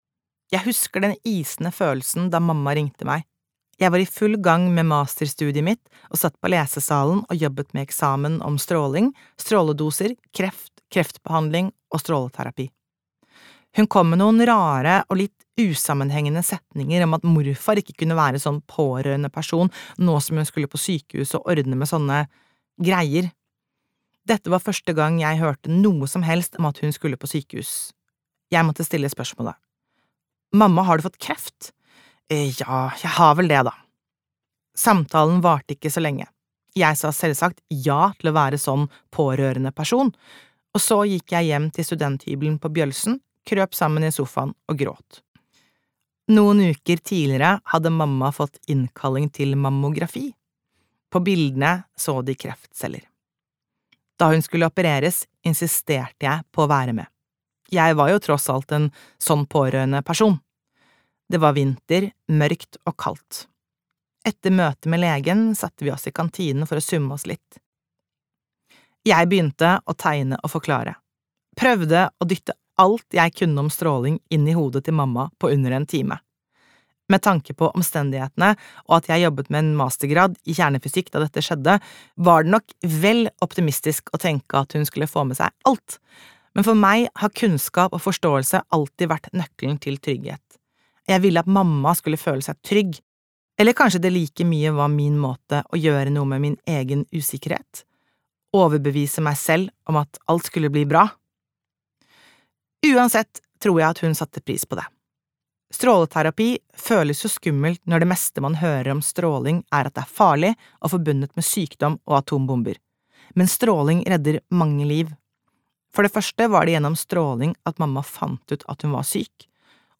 Vi er stjernestøv - kjernefysikk for folk flest (lydbok)